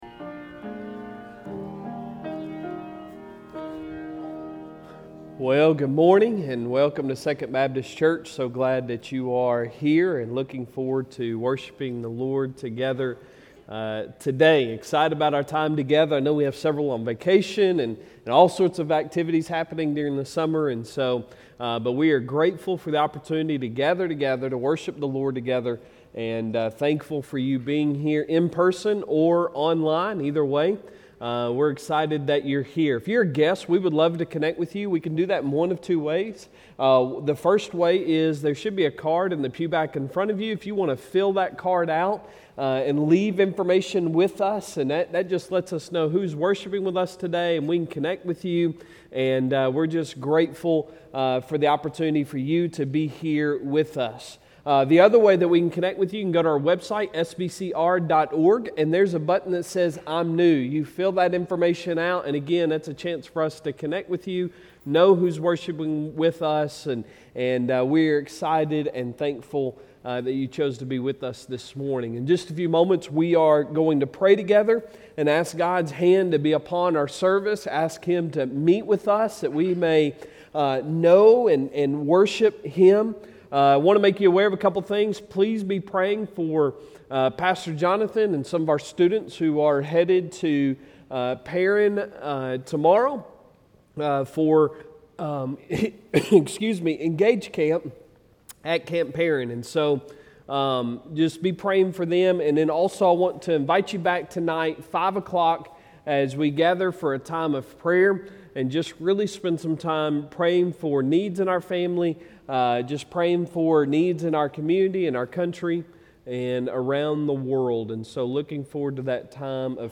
Sunday Sermon July 17, 2022